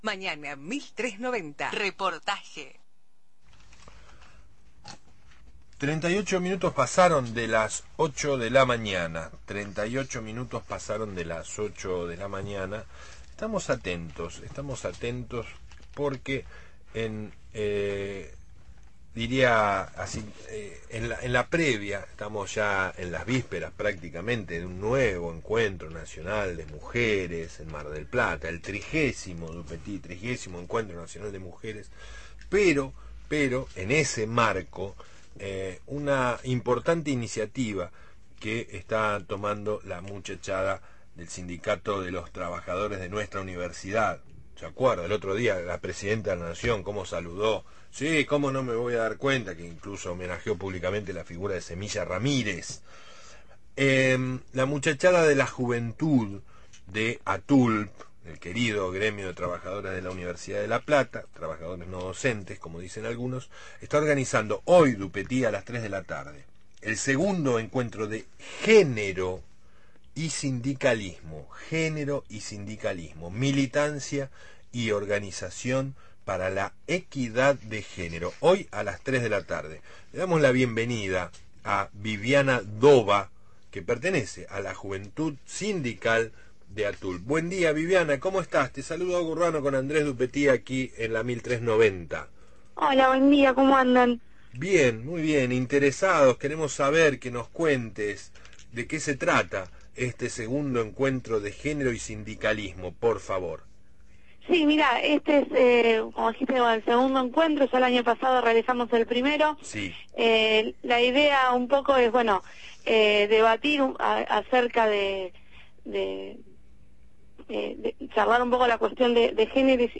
dialogó con «Mañana 1390»  sobre el «2° Encuentro de Género y Sindicalismo»